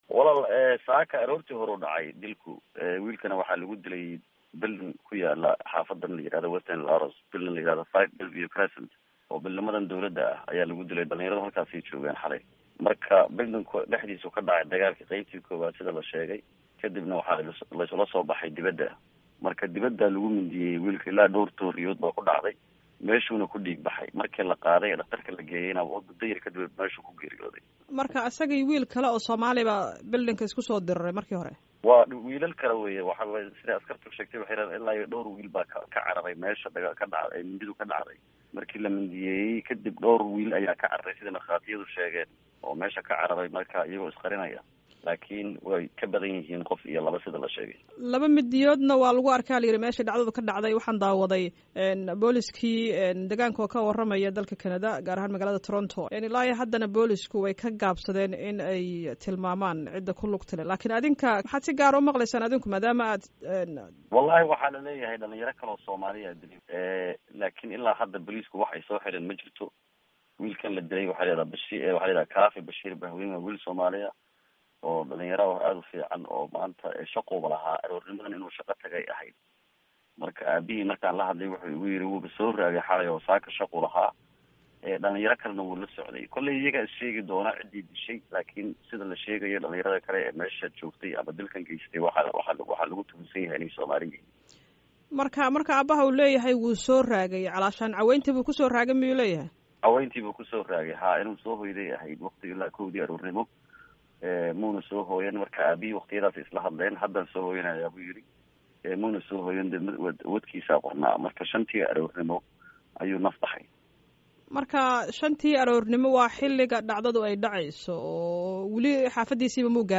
DHAGEYSO WAREYSIGA WIILKA LA DILAY